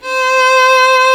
Index of /90_sSampleCDs/Roland - String Master Series/STR_Violin 2&3vb/STR_Vln3 % + dyn
STR VLN3 C 4.wav